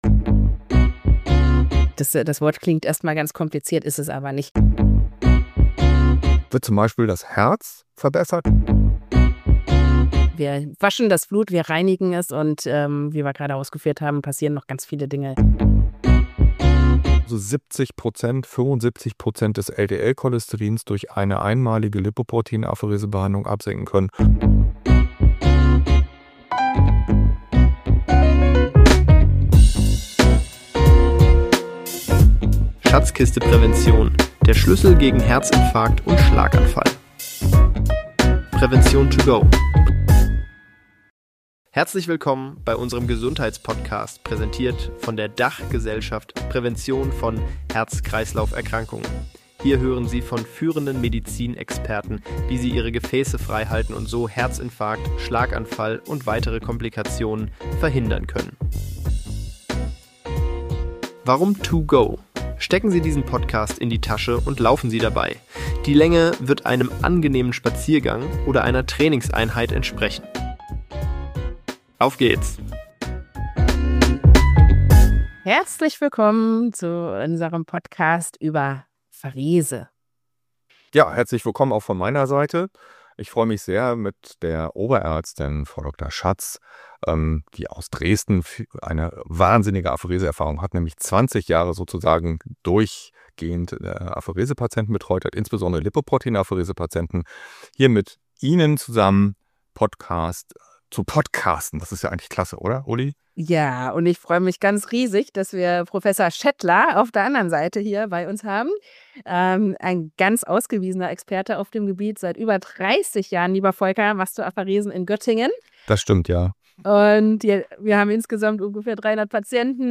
Im Gespräch: